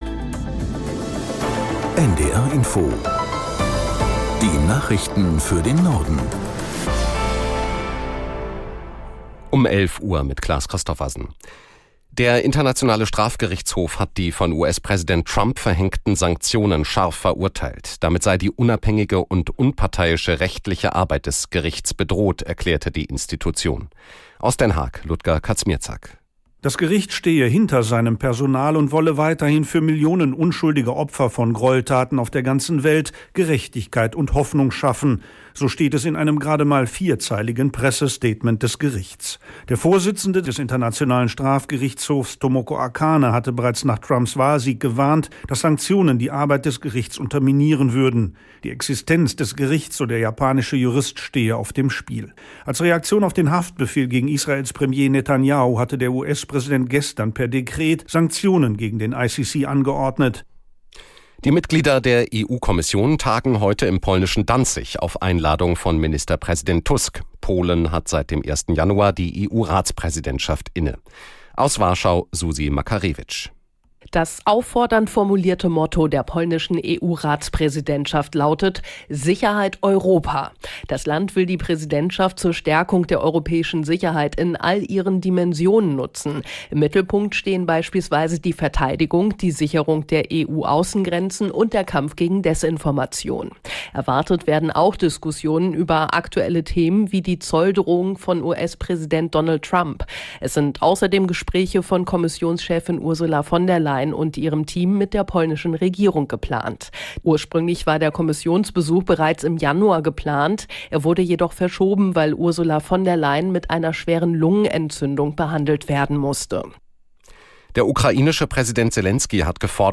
Nachrichten für den Norden. Alle 30 Minuten die aktuellen Meldungen aus der NDR Info Nachrichtenredaktion. Politik, Wirtschaft, Sport. 24 Stunden am Tag - 365 Tage im Jahr.